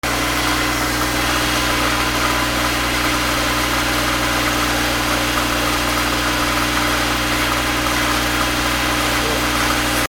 / M｜他分類 / L10 ｜電化製品・機械
脱水機
『ガー』